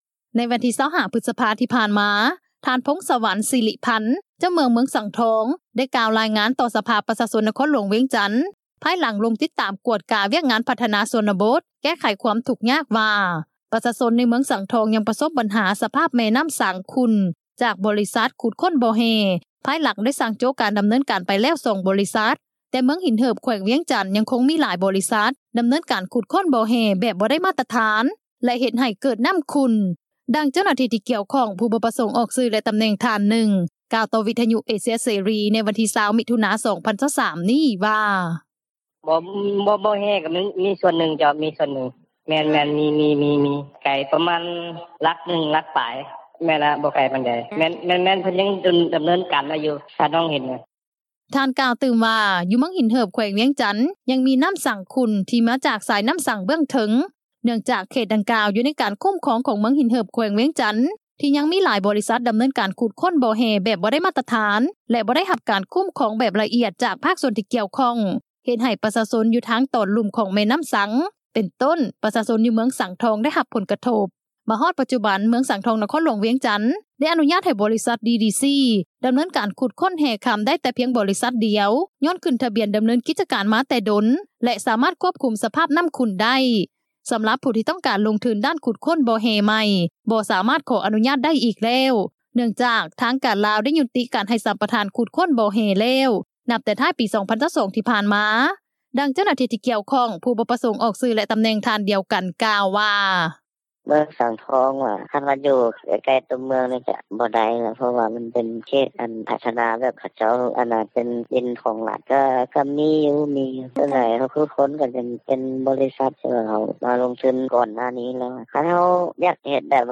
ດັ່ງເຈົ້າໜ້າທີ່ ທີ່ກ່ຽວຂ້ອງ ຜູ້ບໍ່ປະສົງອອກຊື່ ແລະຕໍາແໜ່ງທ່ານນຶ່ງ ກ່າວຕໍ່ວິທຍຸ ເອເຊັຽ ເສຣີ ໃນວັນທີ 20 ມິຖຸນາ 2023 ນີ້ວ່າ:
ດັ່ງຊາວບ້ານ ຢູ່ເມືອງສັງທອງ ນະຄອນຫຼວງວຽງຈັນ ກ່າວວ່າ: